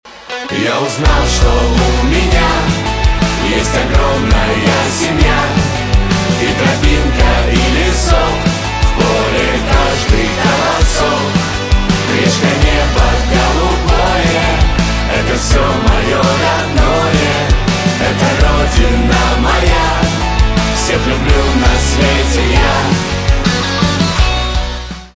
русский рок
патриотические , хор , чувственные
гитара , барабаны